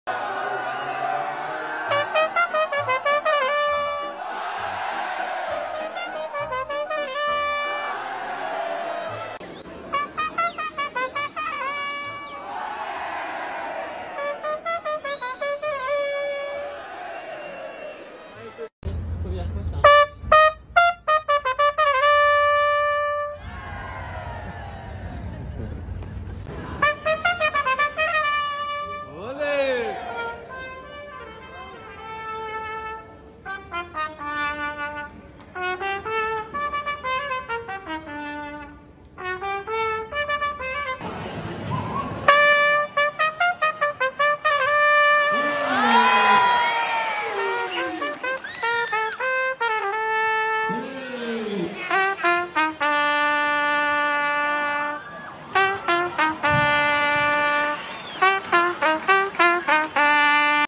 Top 5 Best Ole Trumpet Sound Effects Free Download